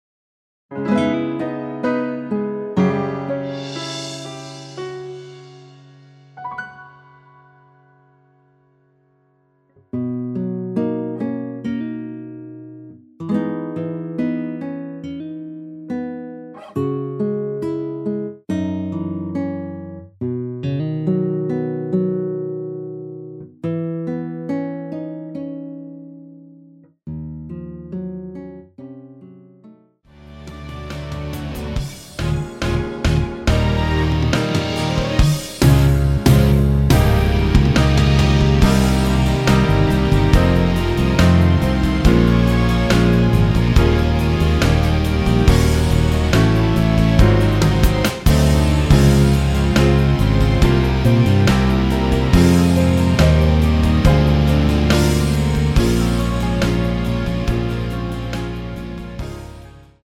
축가로 좋은곡
노래방에서 음정올림 내림 누른 숫자와 같습니다.
음정은 반음정씩 변하게 되며 노래방도 마찬가지로 반음정씩 변하게 됩니다.
앞부분30초, 뒷부분30초씩 편집해서 올려 드리고 있습니다.
중간에 음이 끈어지고 다시 나오는 이유는